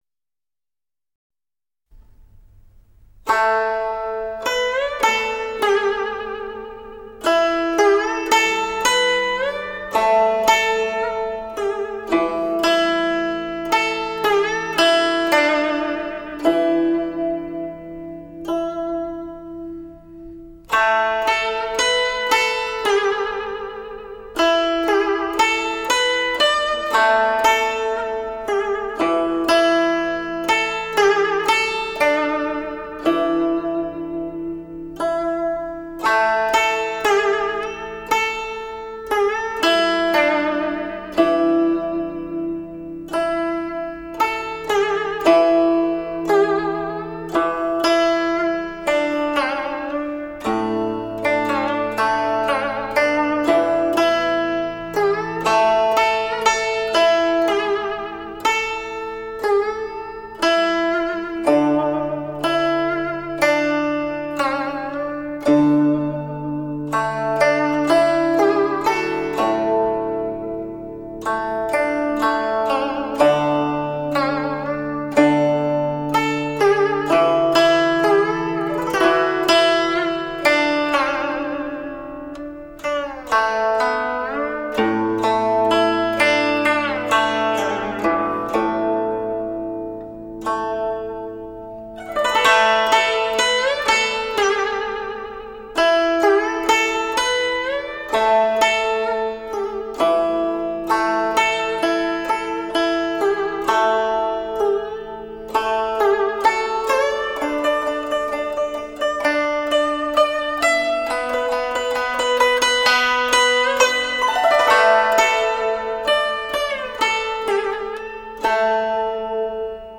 《诗经·郑风》：出其东门（出水莲/古筝）